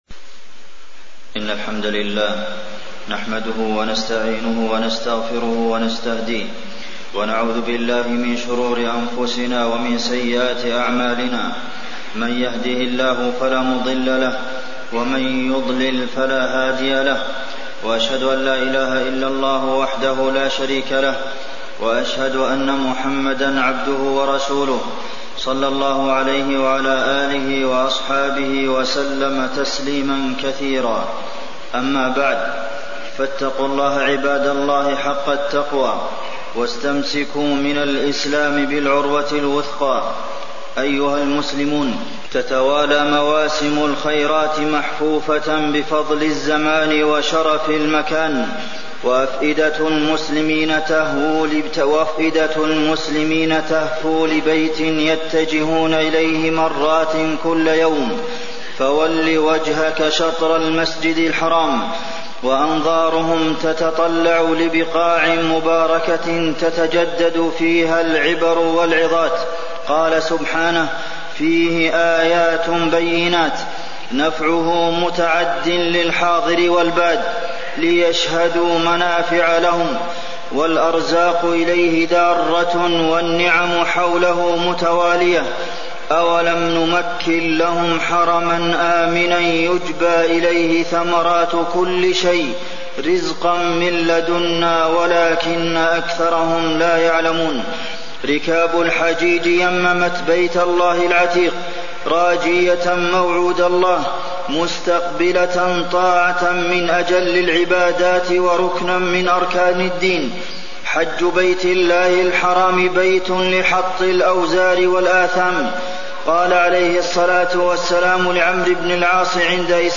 تاريخ النشر ٢٨ ذو القعدة ١٤٣١ هـ المكان: المسجد النبوي الشيخ: فضيلة الشيخ د. عبدالمحسن بن محمد القاسم فضيلة الشيخ د. عبدالمحسن بن محمد القاسم فضل الحج وأيام العشر